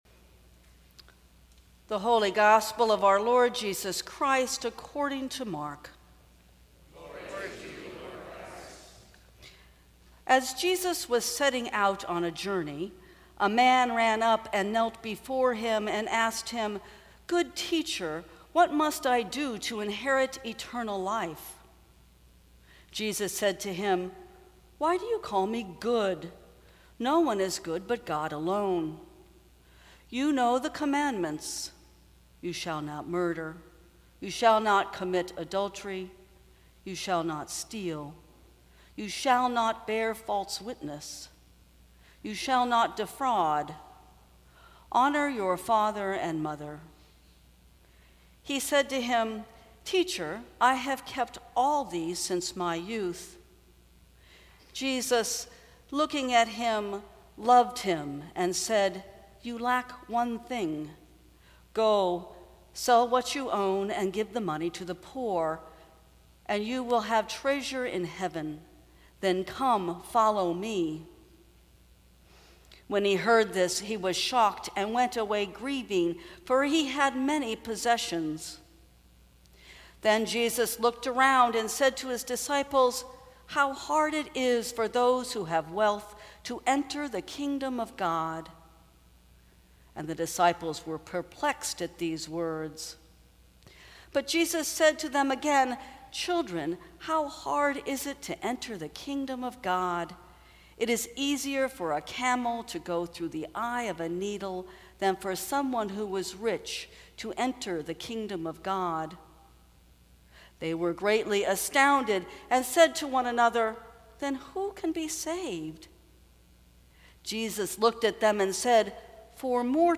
Sermons from St. Cross Episcopal Church Go.